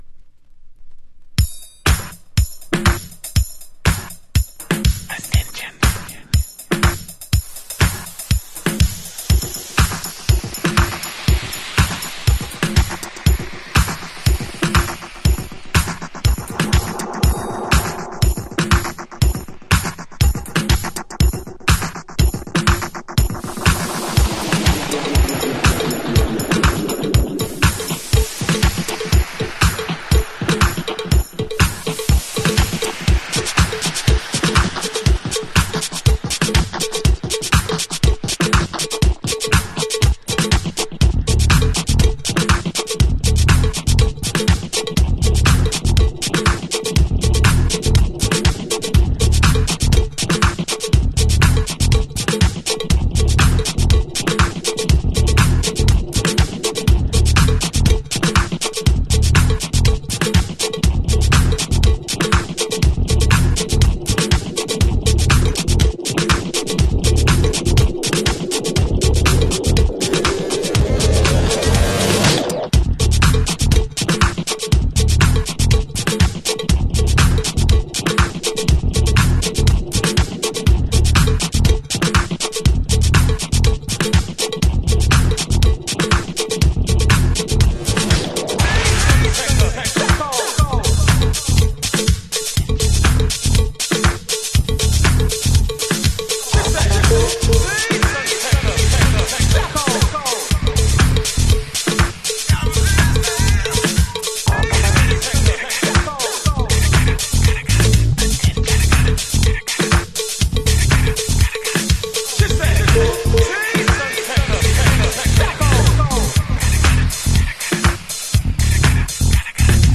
House / Techno
WEST COAST / WICKED HOUSE